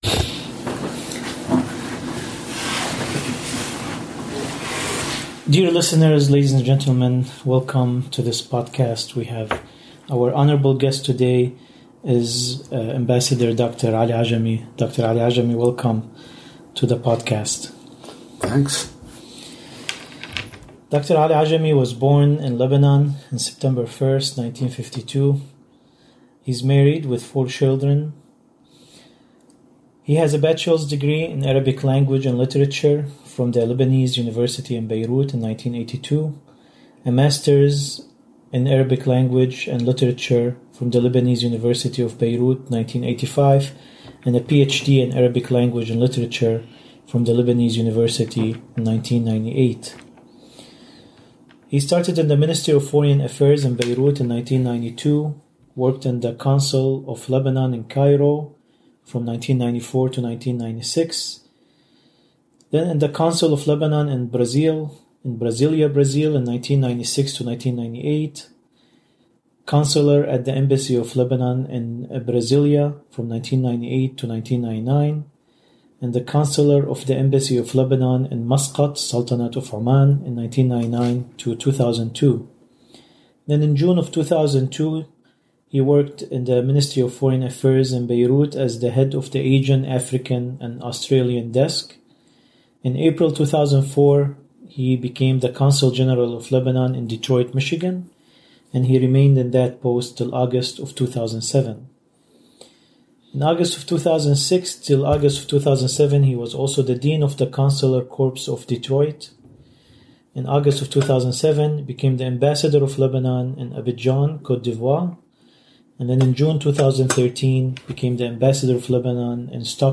In a series of interviews, Ambassador Dr. Ali Ajami will be reflecting on his life journey as a teacher, a journalist, and a diplomat. The interviews will explore history as witnessed by Ambassador Dr. Ali Ajami during his life time, and the various posts he occupied in his professional life.